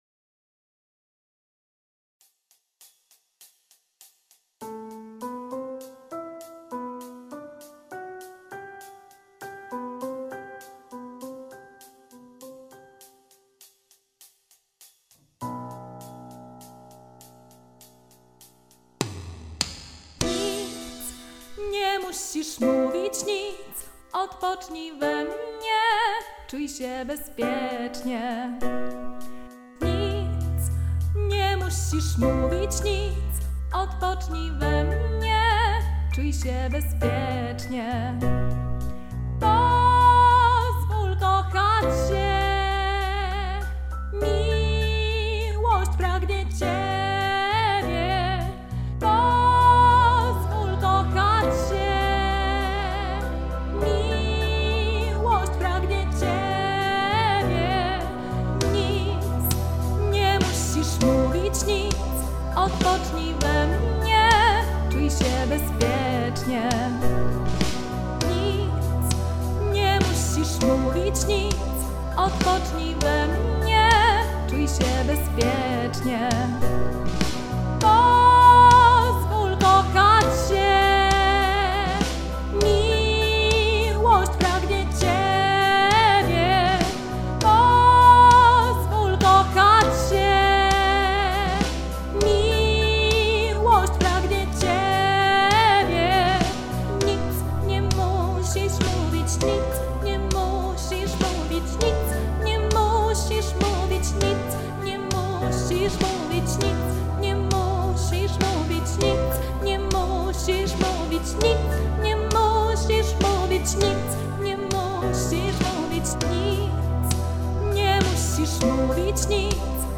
PRZYKŁADOWE WYKONANIA - nagrania w studio